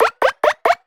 cartoon_boing_climb_run_01.wav